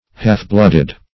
Half-blooded \Half"-blood`ed\, a.